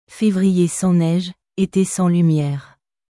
Février sans neige, été sans lumièreフェヴリエ ソン ネージュ エテ ソン リュミエール